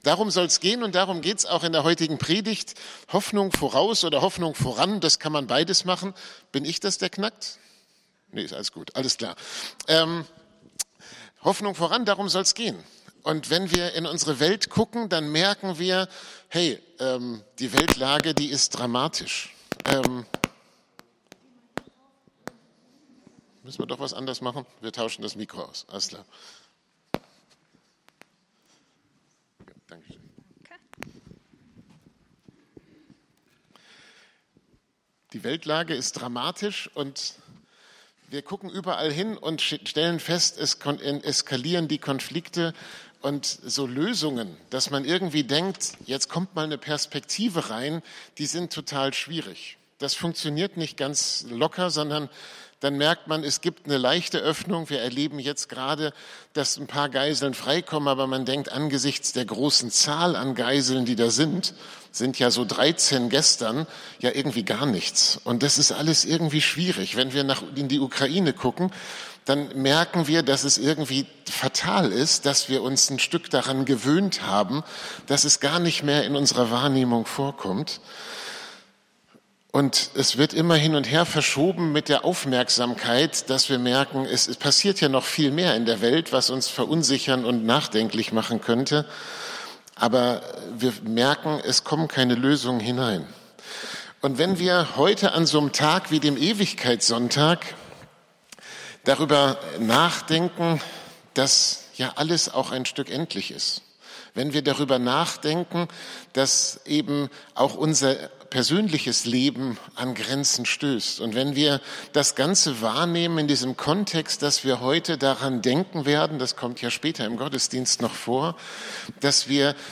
Predigt vom 26.11.2023